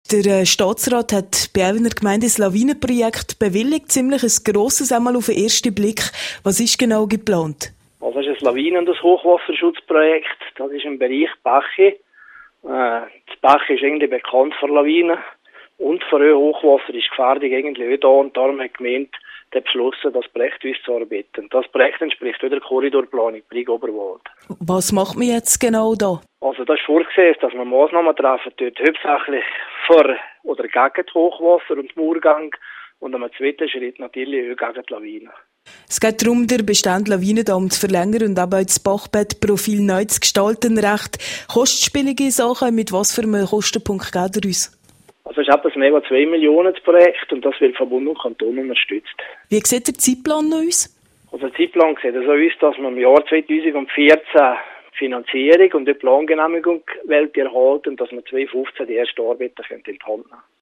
Noch in diesem Jahr will man die Plangenehmigung erhalten./ip Interview mit Gemeindepräsident Norbert Carlen